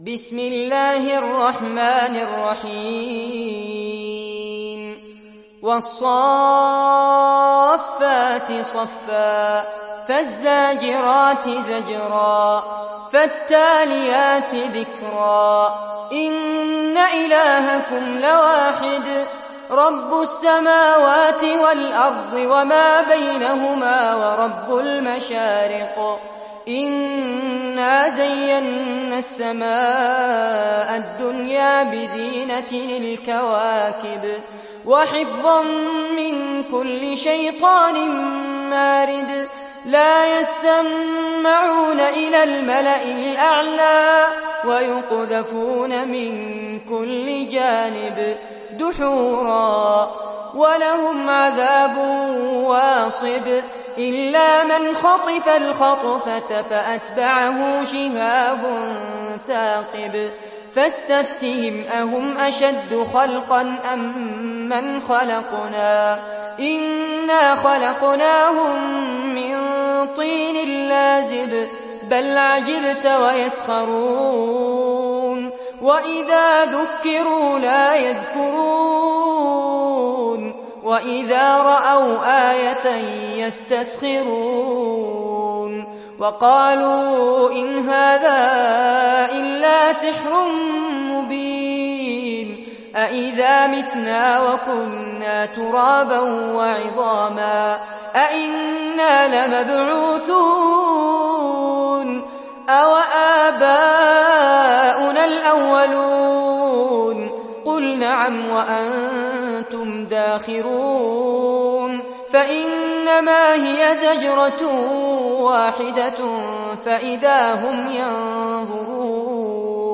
Sourate As Saffat Télécharger mp3 Fares Abbad Riwayat Hafs an Assim, Téléchargez le Coran et écoutez les liens directs complets mp3